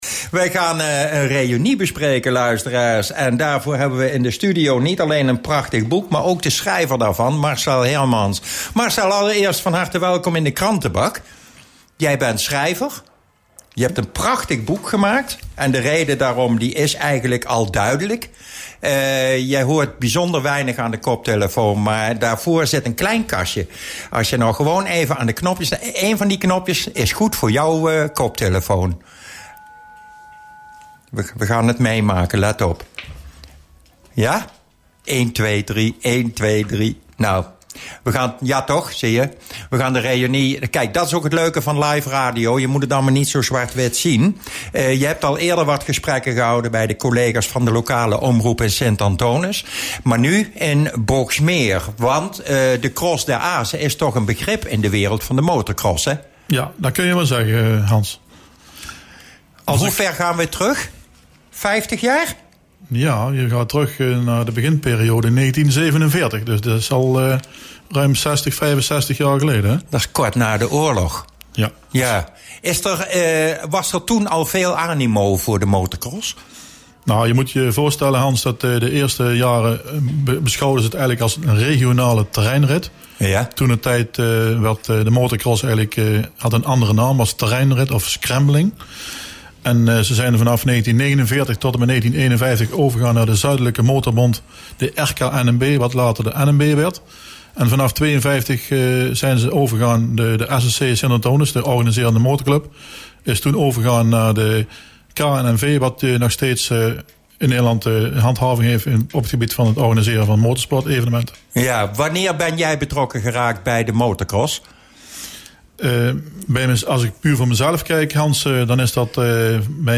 Interview BLOS Radio Interview